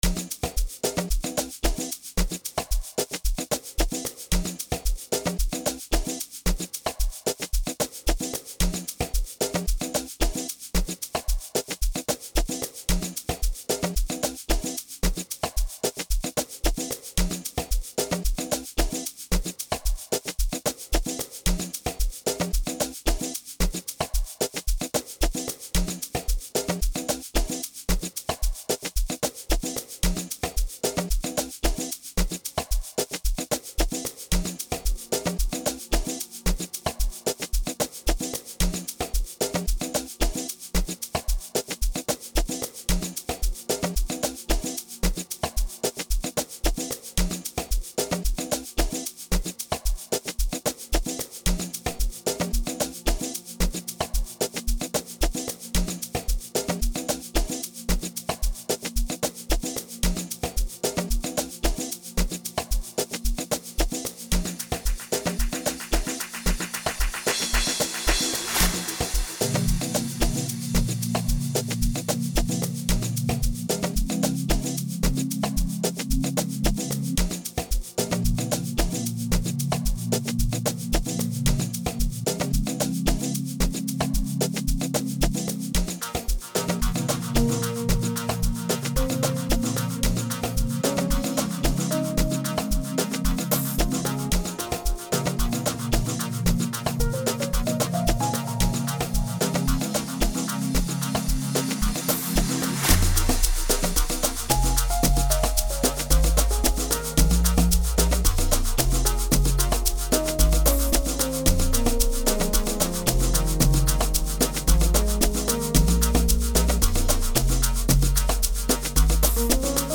Amapiano Tech feel